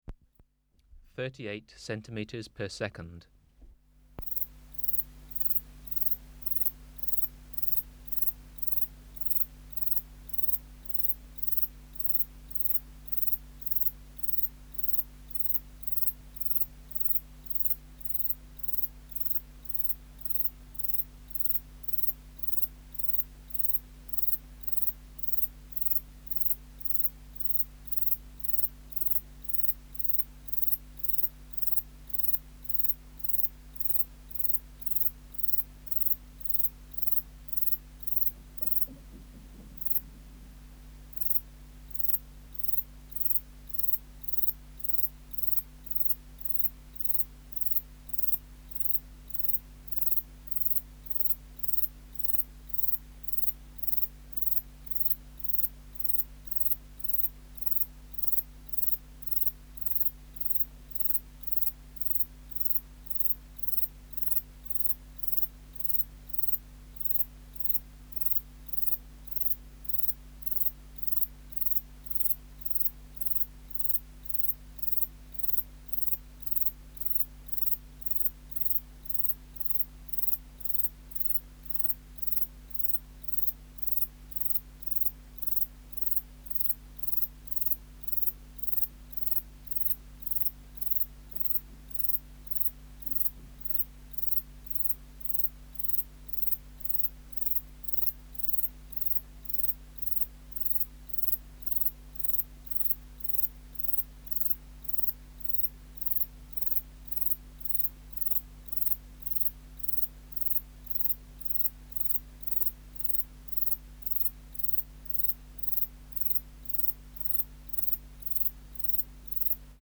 405:17 Platycleis sabulosa (475b) | BioAcoustica
Recording Location: BMNH Acoustic Laboratory
Reference Signal: 1 kHz for 10 s
Substrate/Cage: Large recording cage
Microphone & Power Supply: Sennheiser MKH 405 Distance from Subject (cm): 75